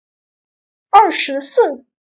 Èrshísì - Ơ sứ xư )